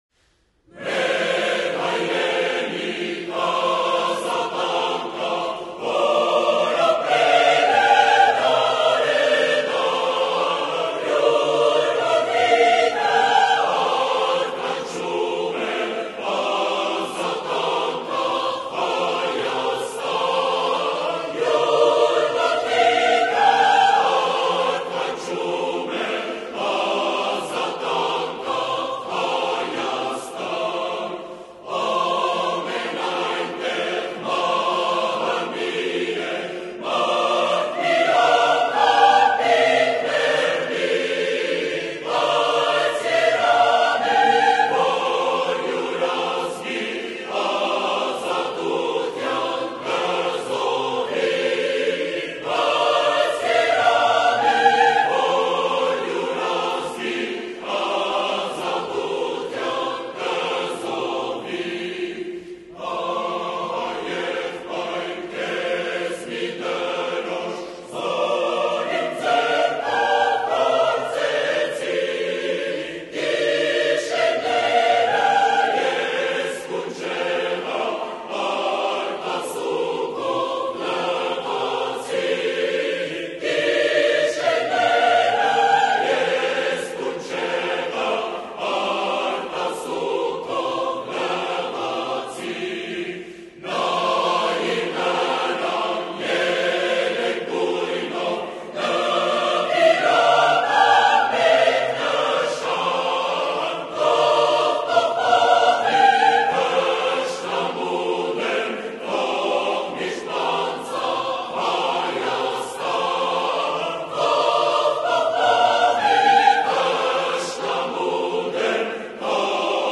Ամբողջական օրհներգը
Երգչախմբի և նվագախմբի կատարմամբ